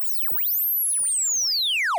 Radio Tuning Reverse.wav